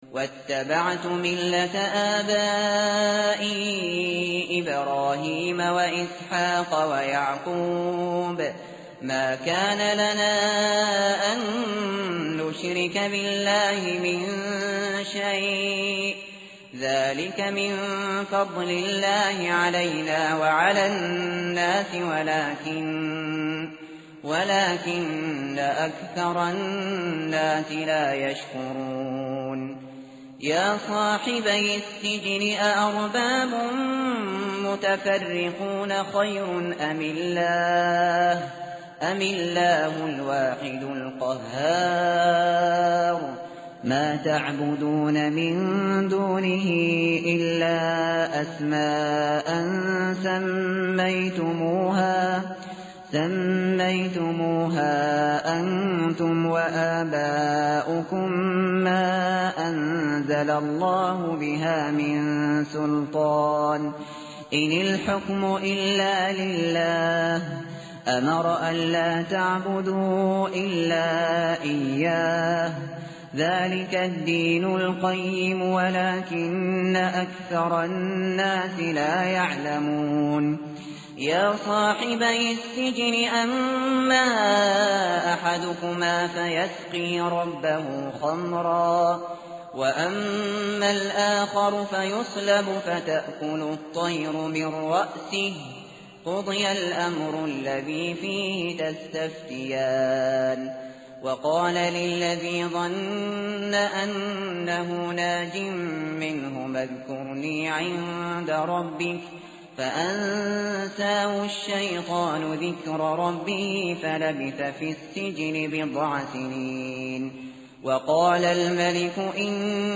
tartil_shateri_page_240.mp3